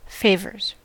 Ääntäminen
Vaihtoehtoiset kirjoitusmuodot (amerikanenglanti) favours Ääntäminen US Haettu sana löytyi näillä lähdekielillä: englanti Käännöksiä ei löytynyt valitulle kohdekielelle. Favors on sanan favor monikko (amerikanenglanti).